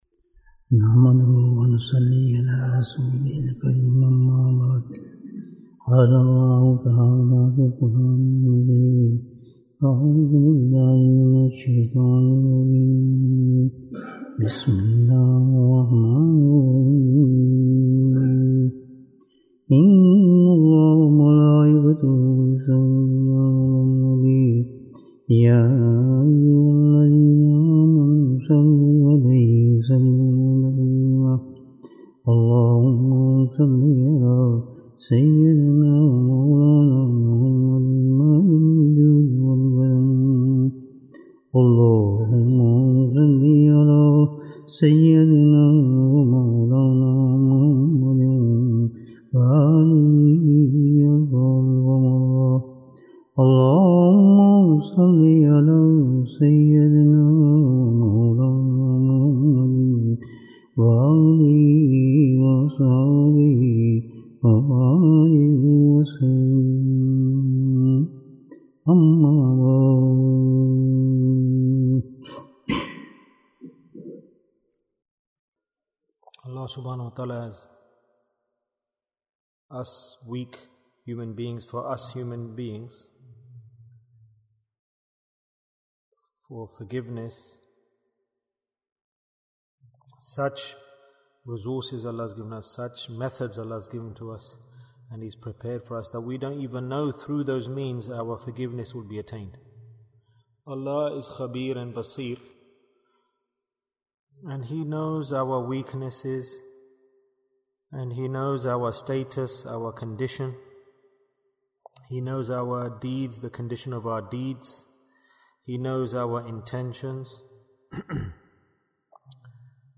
What do you Gain from Hospitality? Bayan, 61 minutes15th December, 2022